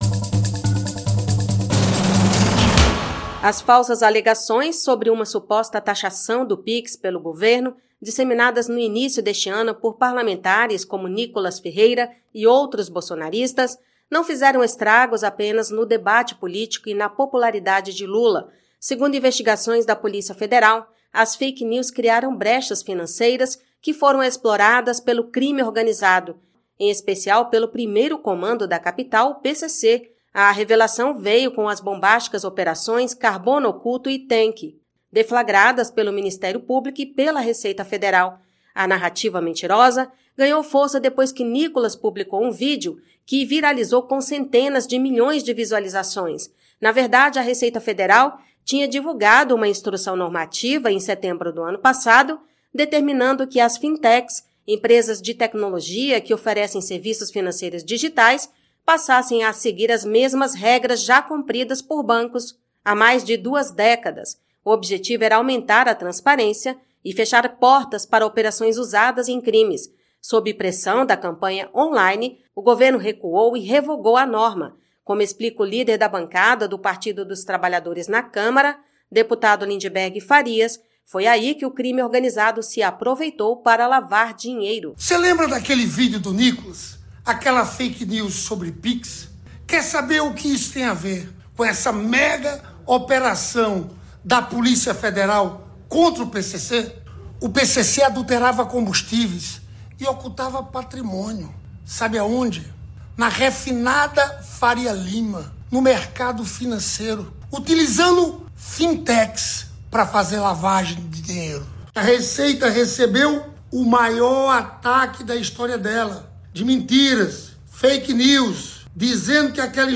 BOLETIM | Fake news sobre Pix ajudaram PCC a lavar bilhões